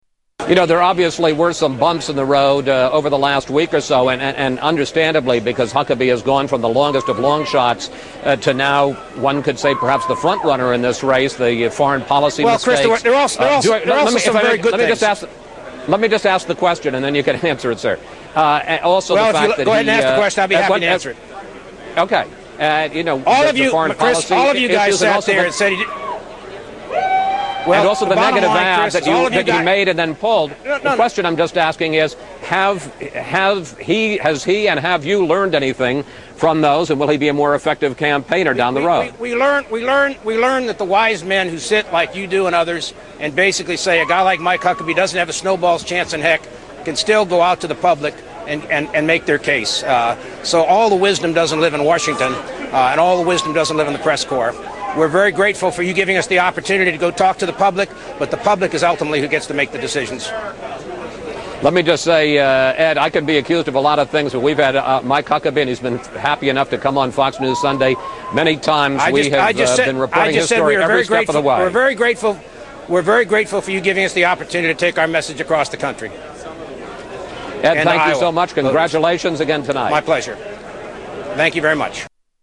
Contentious Interview with Ed Rollins